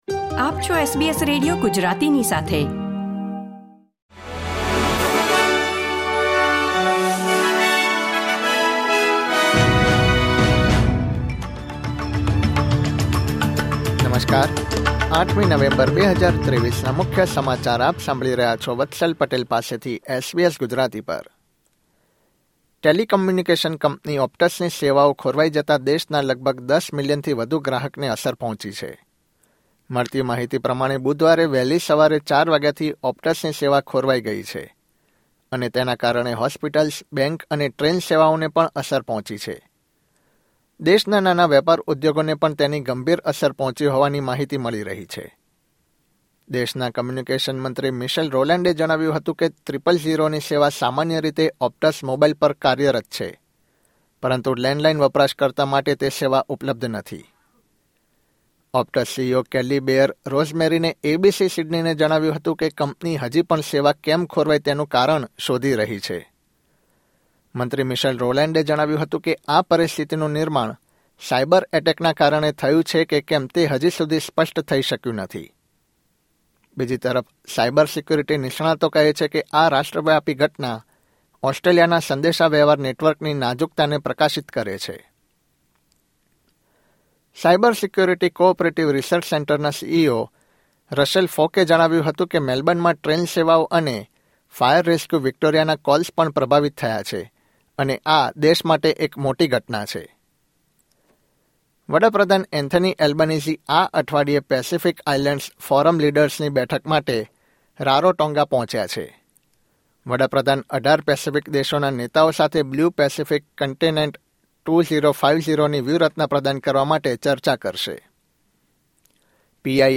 SBS Gujarati News Bulletin 8 November 2023